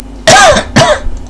cougth.wav